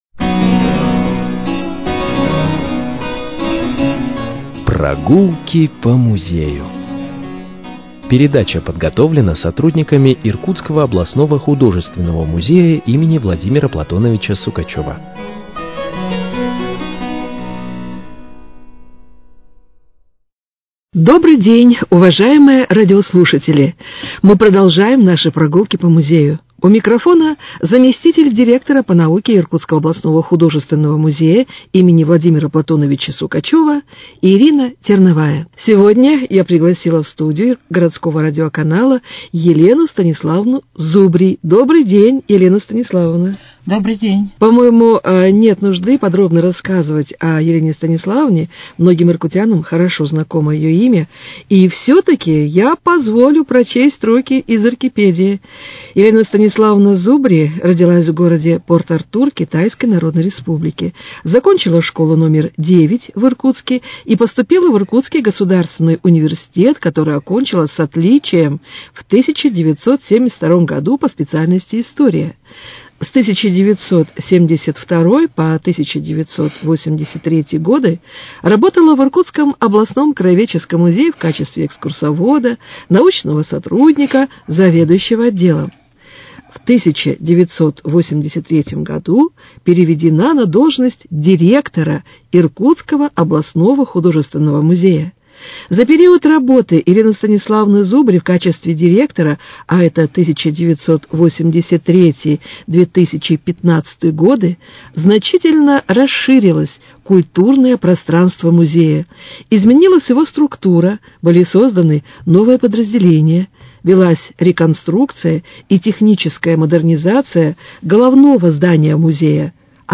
Прогулки по музею: Передача посвящена первому директору Иркутского художественного музея Григорию Дудину